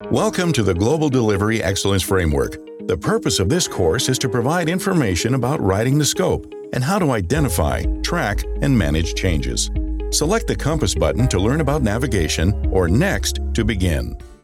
Commercieel, Opvallend, Veelzijdig, Warm, Zakelijk
E-learning
He records from a professionally appointed home studio for clients across the globe.
His voice can be described as Warm, Deep, Authoritative, Calming, Confident, Authentic, Rustic, and Masculine.